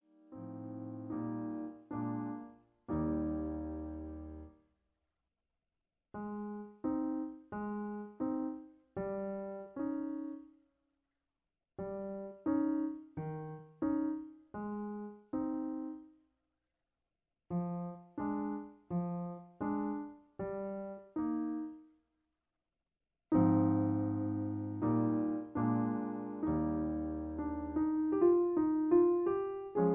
In A flat. Piano Accompaniment